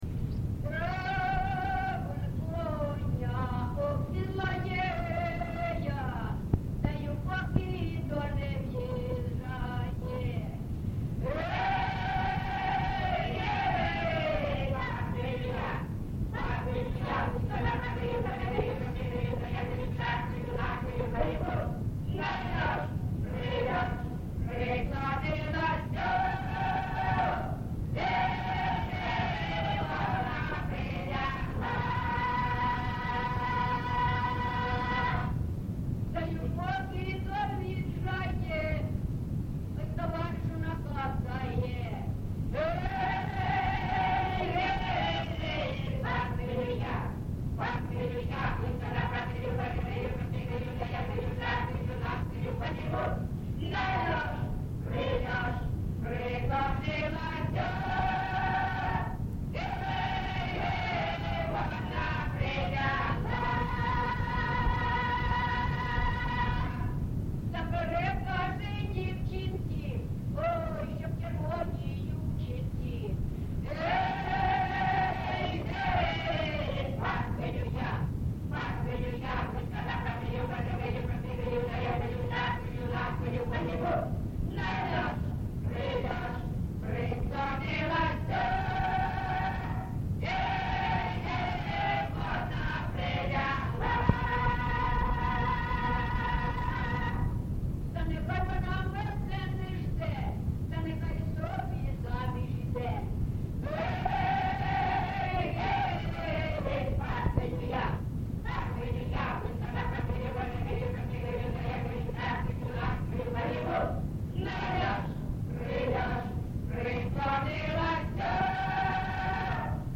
ЖанрСолдатські, Жартівливі
Місце записус. Семенівка, Краматорський район, Донецька обл., Україна, Слобожанщина